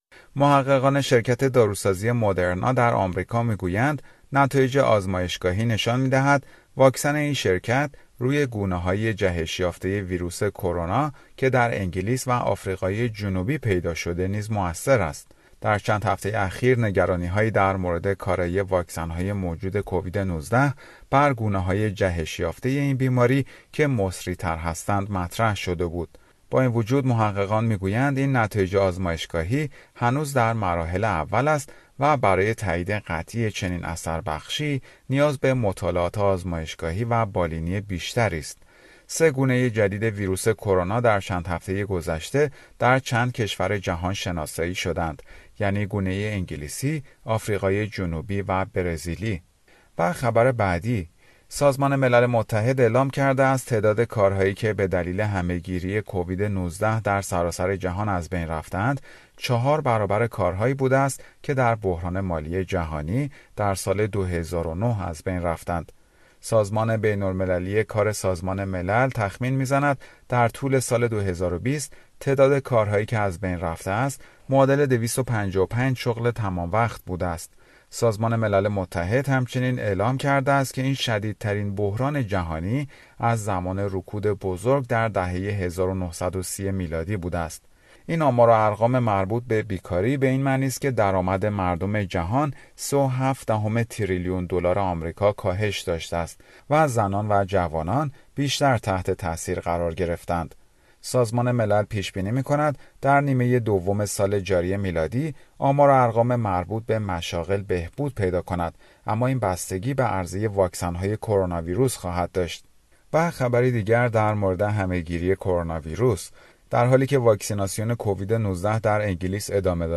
اخبار کوتاه اس بی اس فارسی درباره کووید-۱۹